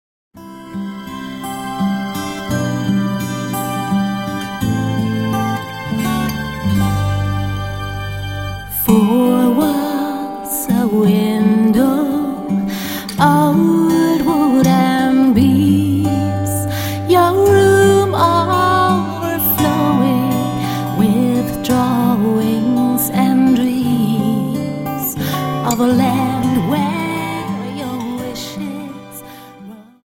Dance: Slow Waltz 28